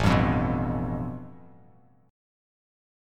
A7sus2#5 chord